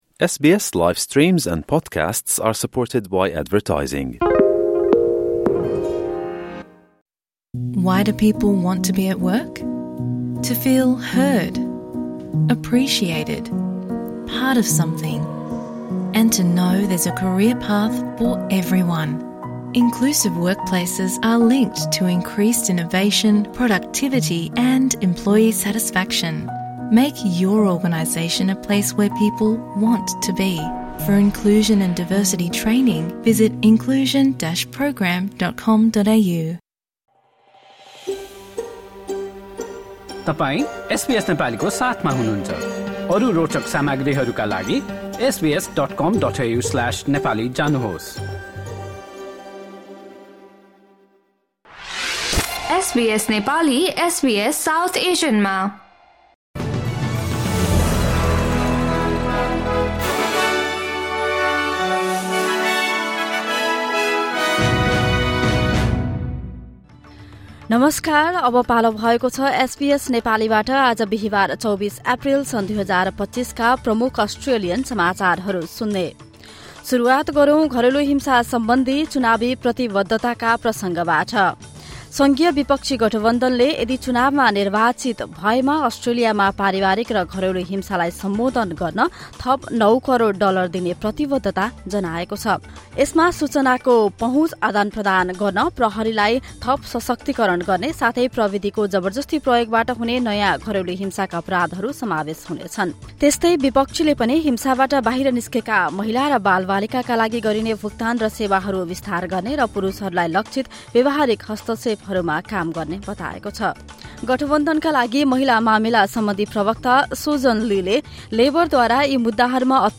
एसबीएस नेपाली प्रमुख अस्ट्रेलियन समाचार: बिहीवार, २४ एप्रिल २०२५